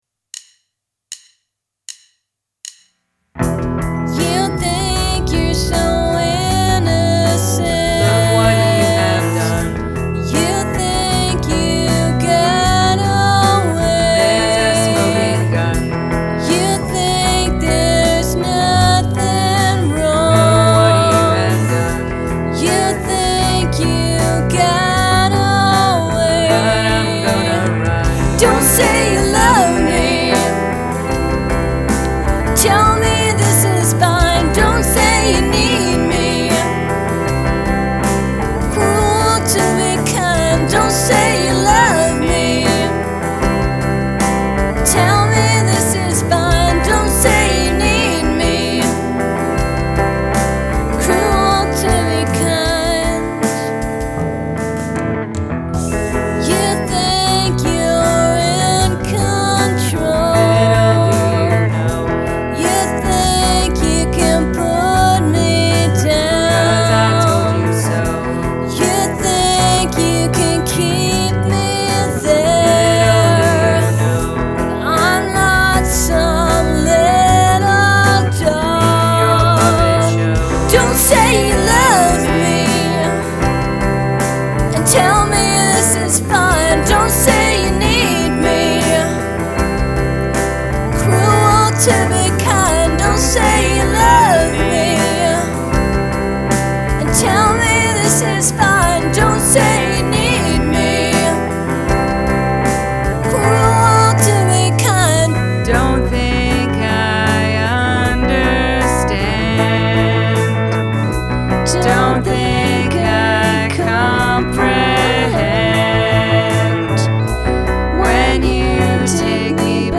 vocals, keyboards
Omnichord, vocals
guitar, drum pedal